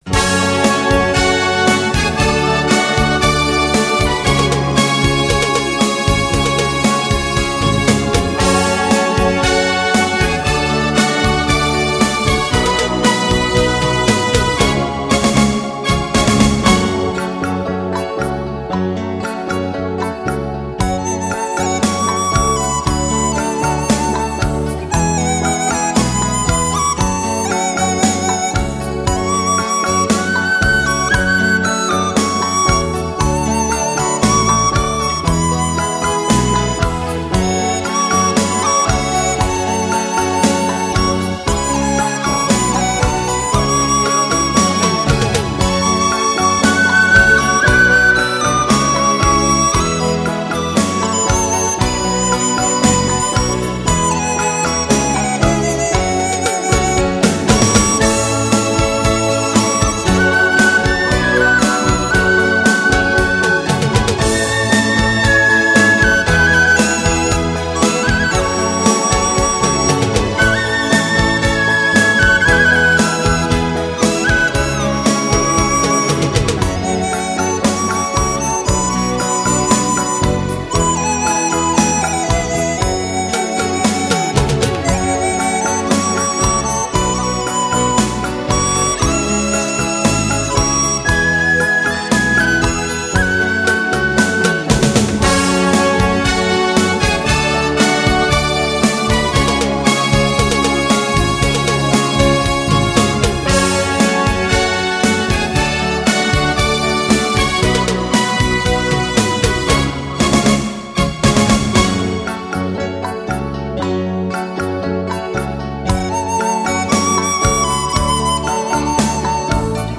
品质保证 完美呈现 绕音非凡 立体高清
音场超级宽广，音效超强动感，人声清晰震撼，节奏令你人神共奋。
笛子